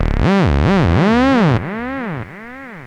• monodelay45.wav
Loudest frequency 575 Hz Recorded with monotron delay and monotron - analogue ribbon synthesizer
monodelay45_U8F.wav